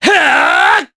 Kasel-Vox_Attack4_jp.wav